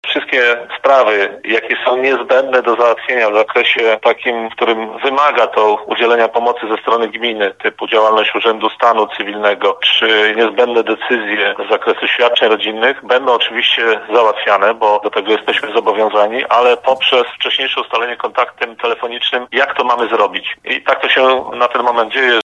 Mówi burmistrz, Wiesław Ordon.